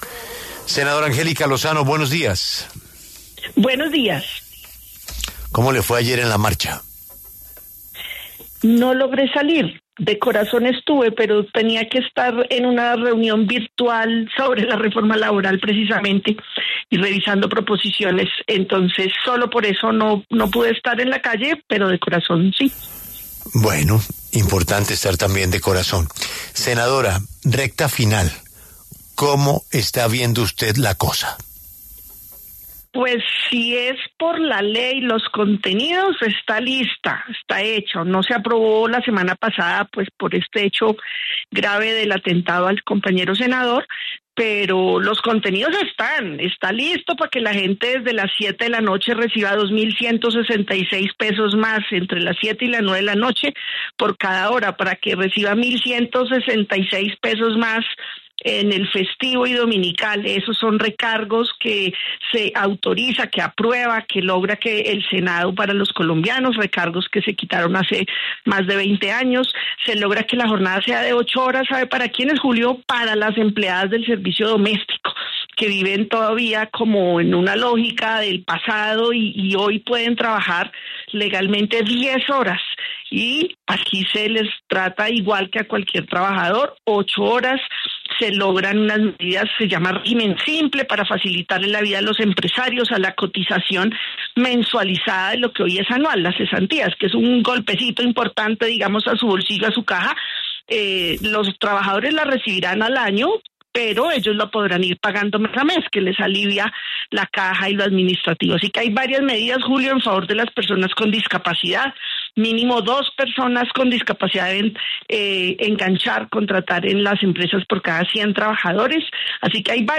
En diálogo con La W, la senadora Angélica Lozano se pronunció sobre la reforma laboral que se debate en el Congreso.